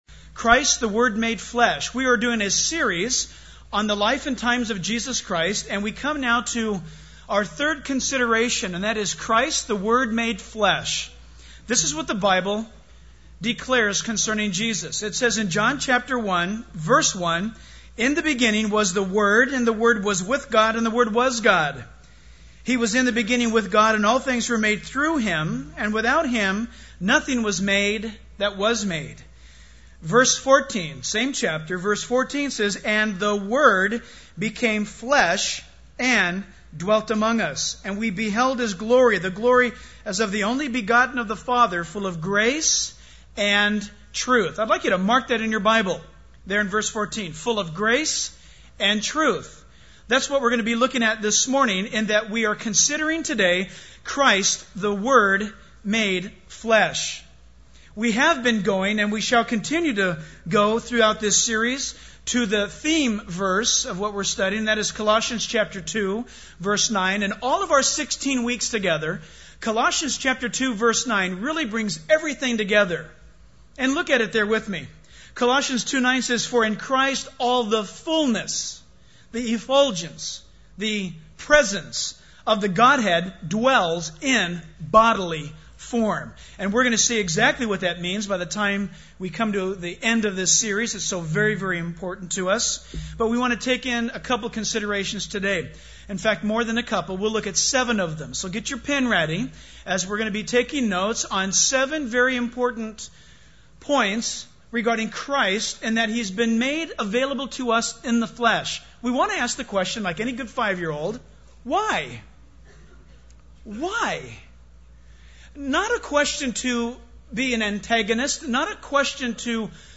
In this sermon, the preacher discusses the importance of Jesus coming into the world as the perfect sacrifice for humanity. He explains that the law hangs over us, intimidating and condemning us, but Jesus came to fulfill the law perfectly.